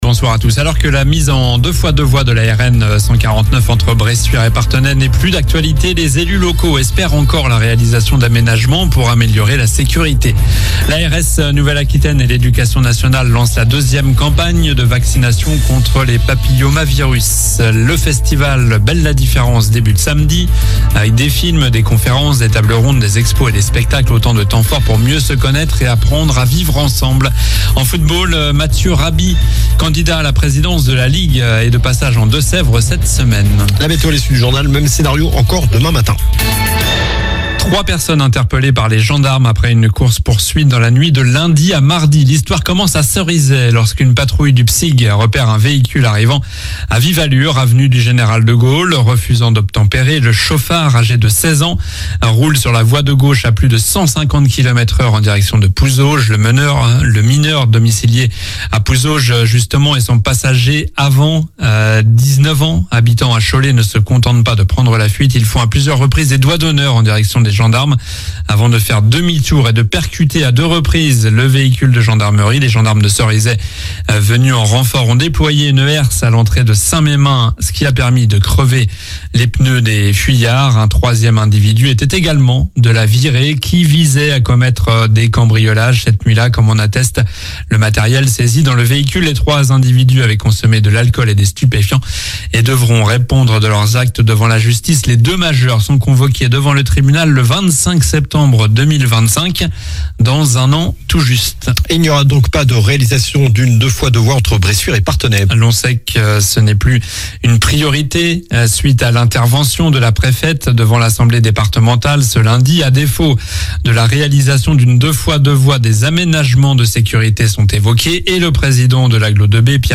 Journal du mercredi 25 septembre (soir)